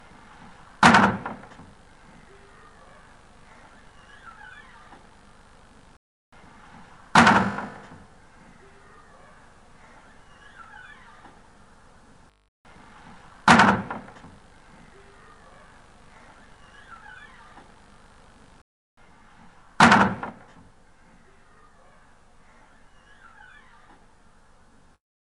1st. A hummy recording of my neighbour slamming their rubbish bin lid (and their door squeaking).
2nd. my dehummed version of 1 using my plug-in with he lowest possible amount of dehum, Gibbs ringing immediately follows the loud bin lid slam.
3rd. Same as 1.
4th. Modified version of your dehummer applied , No hum and no Gibbs ringing !